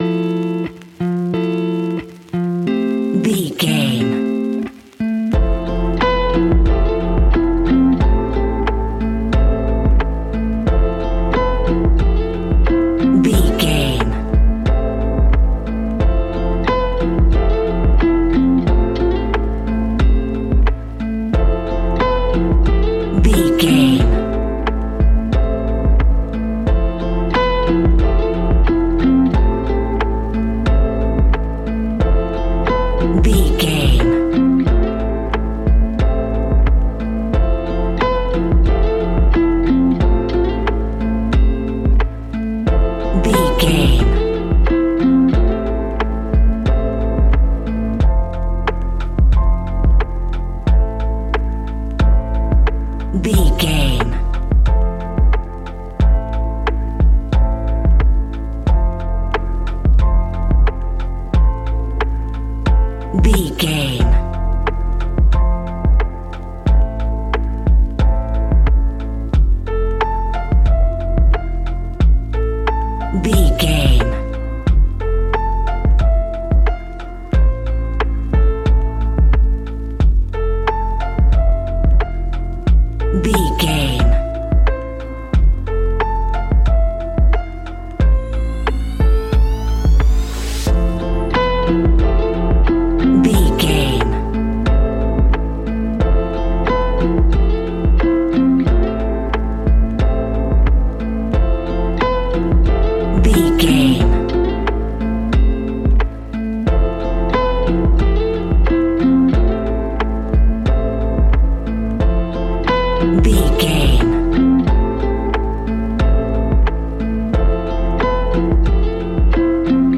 Ionian/Major
A♭
laid back
Lounge
sparse
new age
chilled electronica
ambient
atmospheric
morphing